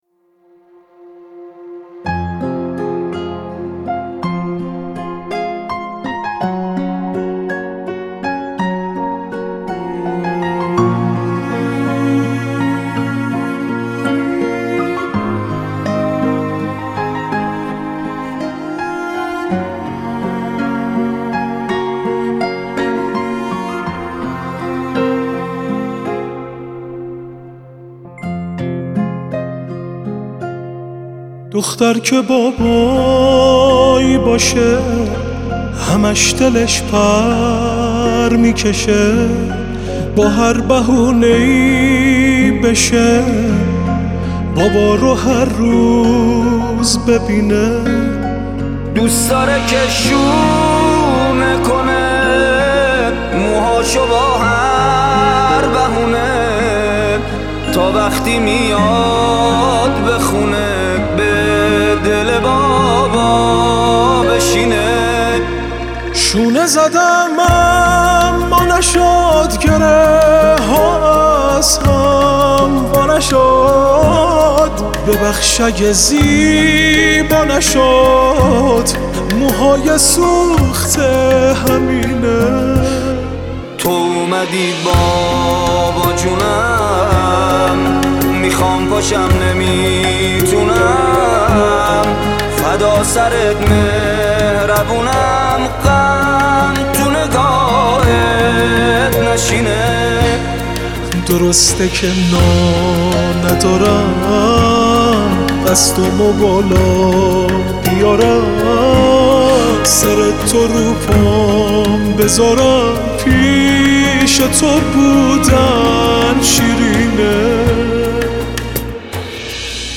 قطعه ی عاشورایی